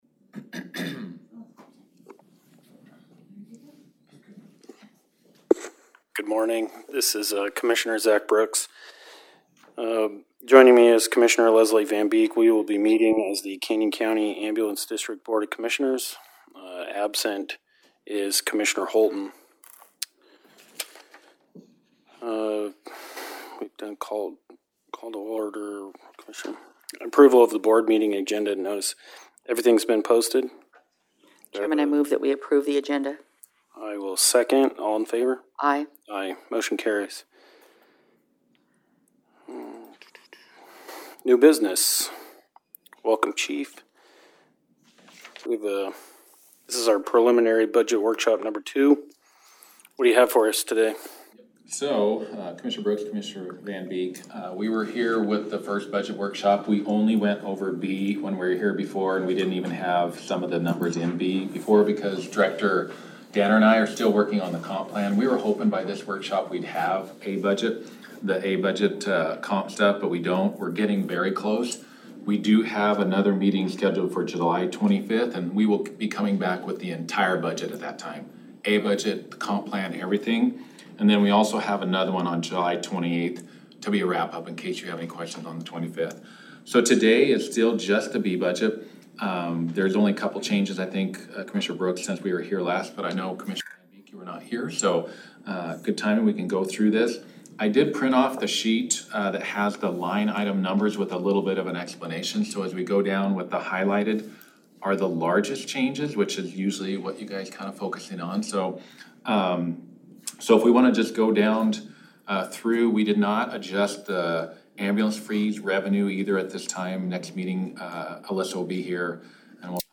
Public Hearing